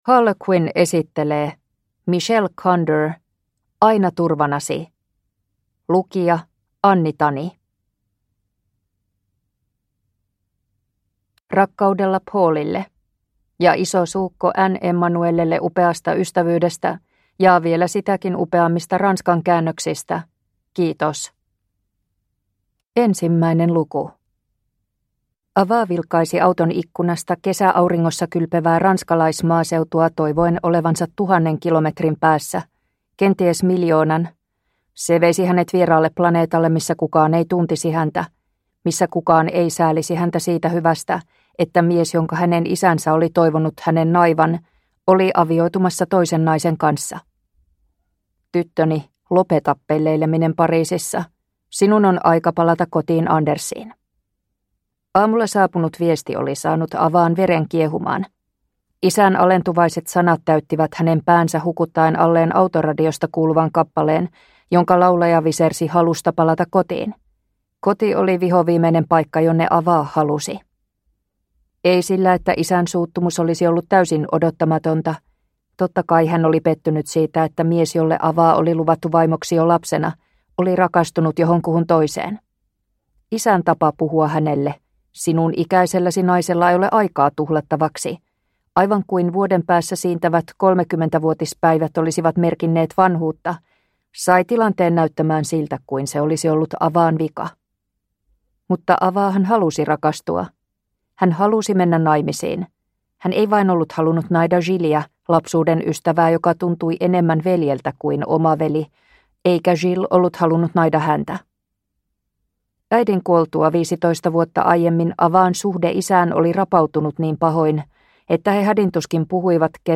Aina turvanasi (ljudbok) av Michelle Conder